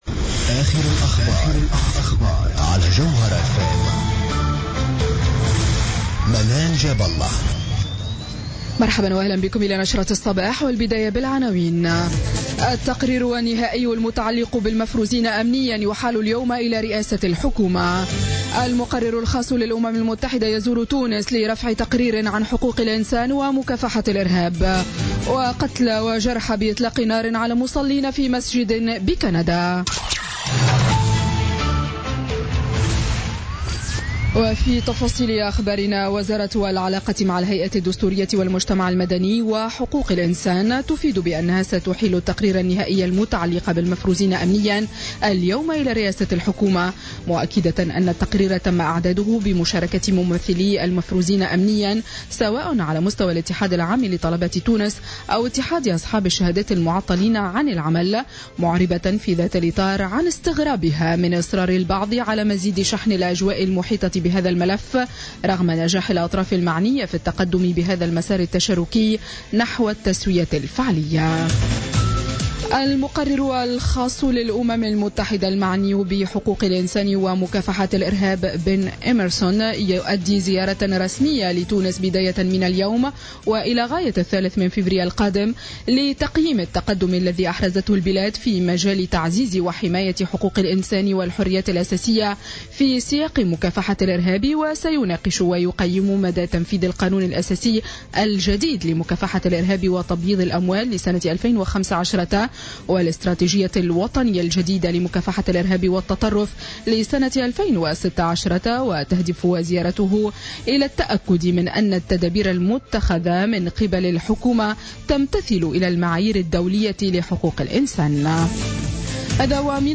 نشرة أخبار السابعة صباحا ليوم الإثنين 30 جانفي 2017